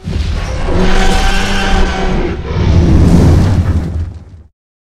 taunt.ogg